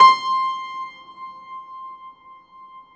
53c-pno18-C4.wav